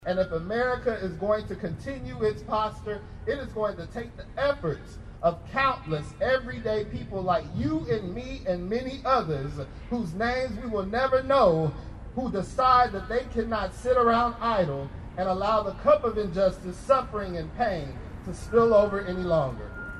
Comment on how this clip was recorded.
Kansas State University honored the life and legacy Dr. Martin Luther King Jr. with their annual candle lighting and wreath laying ceremony on campus Friday.